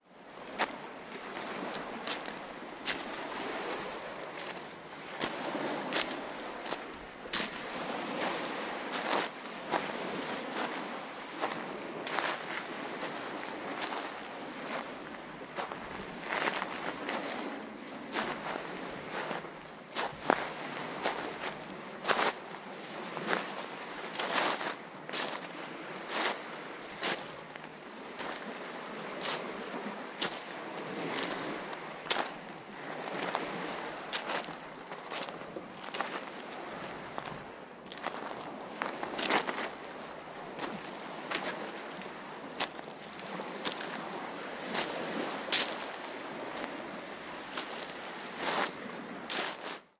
多塞特郡查茅斯海滩的夏季海浪 " 多塞特郡查茅斯海滩的夏季海浪
描述：在多塞特郡的Charmouth海滩，一个美好的夏日夜晚。轻柔的海浪扰乱了卵石。
Tag: 海滩 多塞特 海浪 现场录音 Charmouth 立体声 夏天 卵石